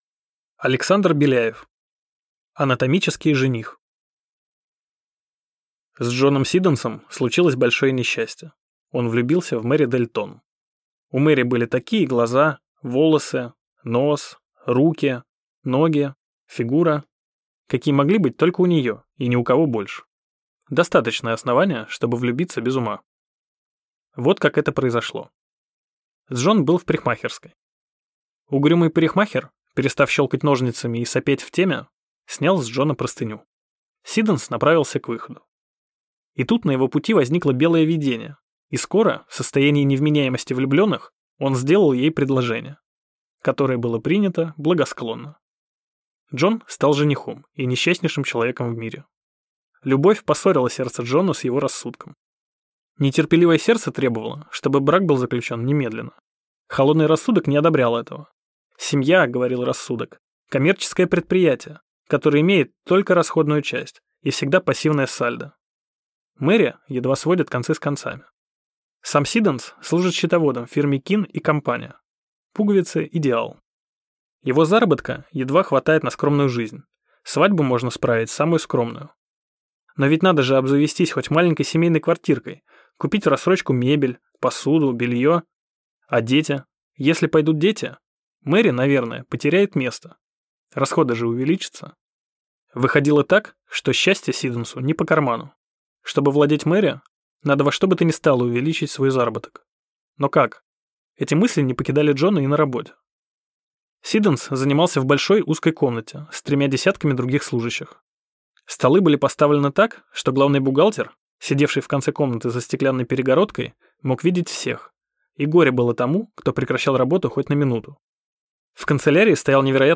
Аудиокнига Анатомический жених | Библиотека аудиокниг